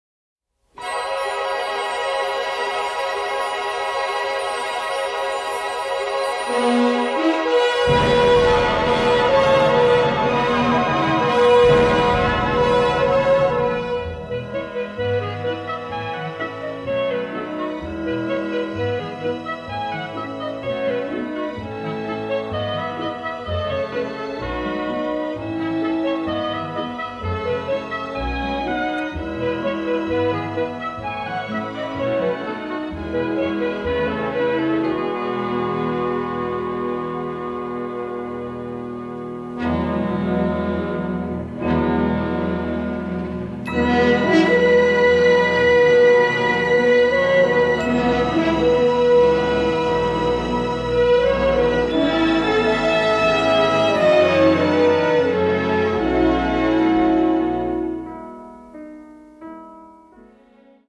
score is lush and full of kaleidoscopic orchestral color